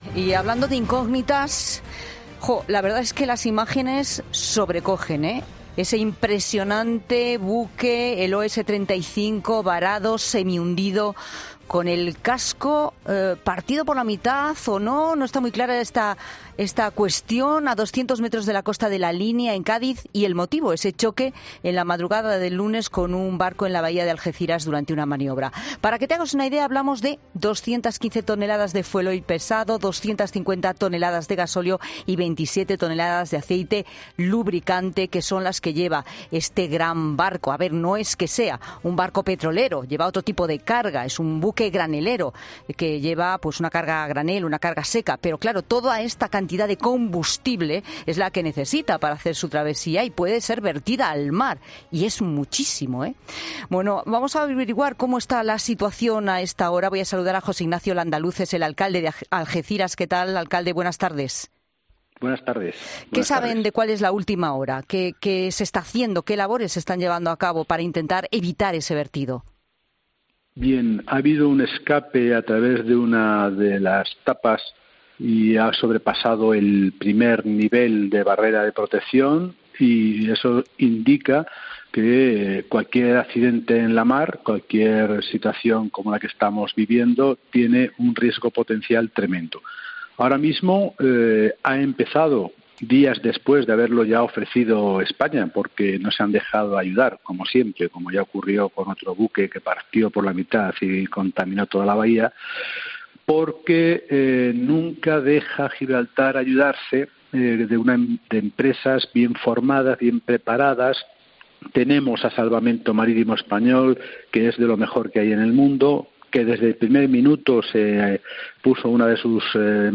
José Ignacio Landaluce, alcalde de Algeciras, en COPE: "Gibraltar vuelve a mostrar irresponsabilidad"